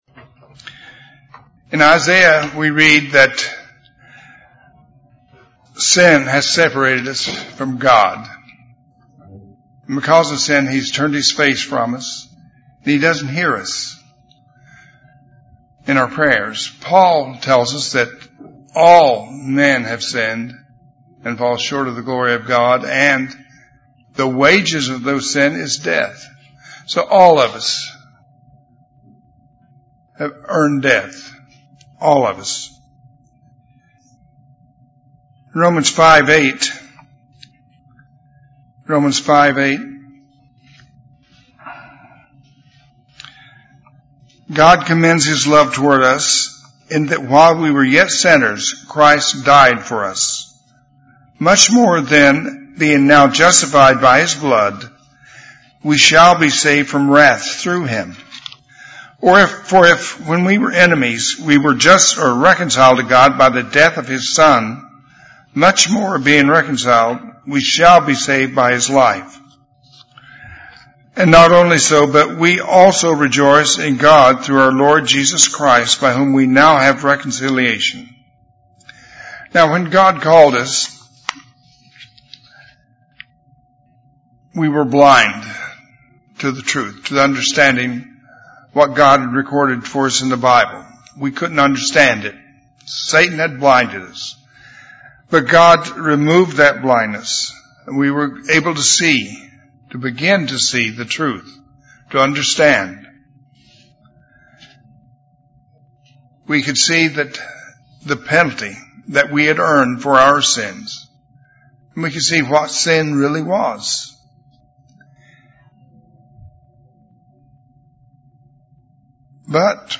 Sermons
Given in Little Rock, AR